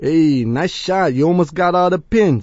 gutterball-3/Gutterball 3/Commentators/Louie/l_ehniceshotalmostall2.wav at 893fa999aa1c669c5225bd02df370bcdee4d93ae
l_ehniceshotalmostall2.wav